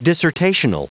Prononciation du mot dissertational en anglais (fichier audio)
Prononciation du mot : dissertational